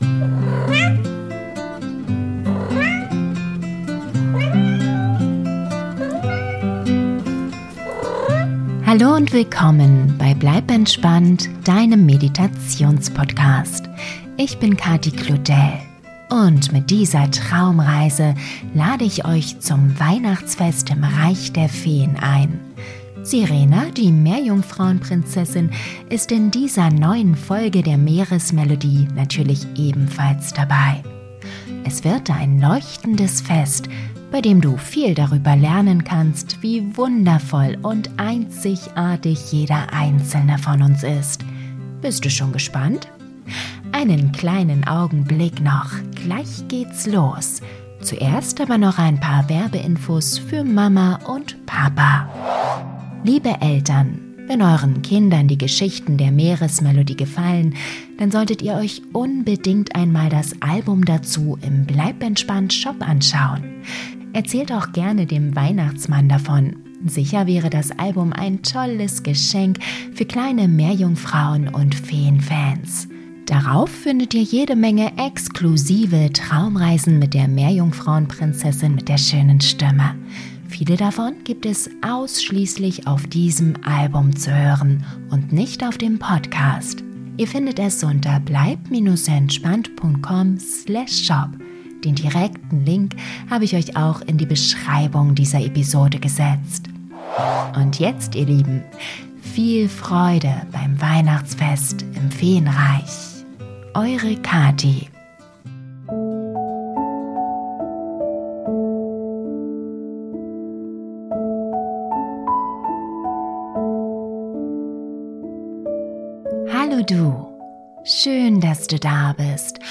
Diese Gute Nacht Geschichte ins Reich der Feen ist das perfekte Werkzeug, um dein Kind zur Ruhe zu bringen und es in seiner inneren Stärke und Einzigartigkeit zu bestärken.